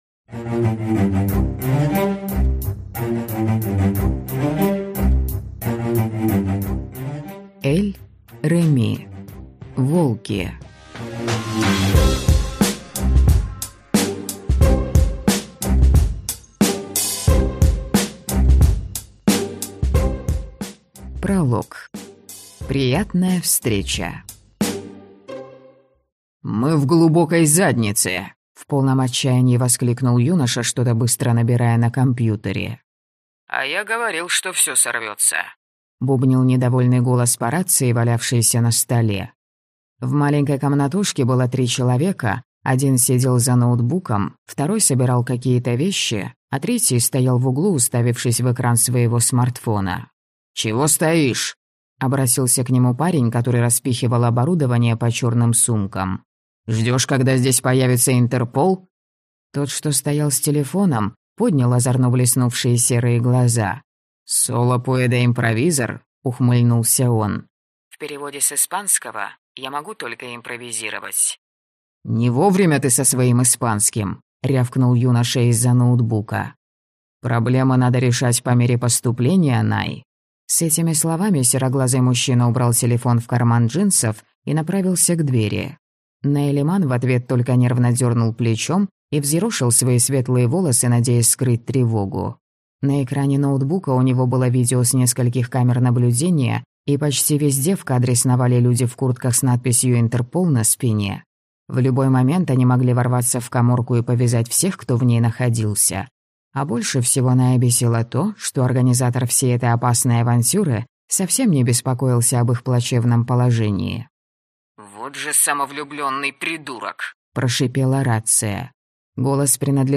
Аудиокнига Волки | Библиотека аудиокниг